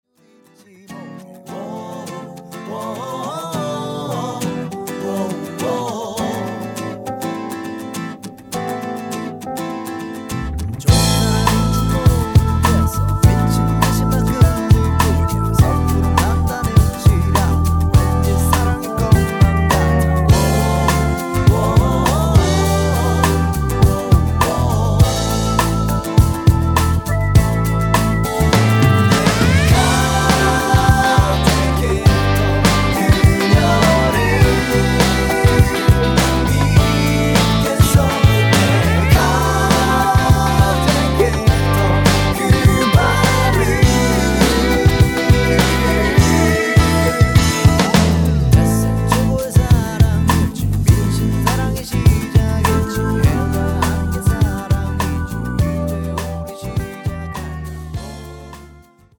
음정 원키 3:07
장르 가요 구분 Voice MR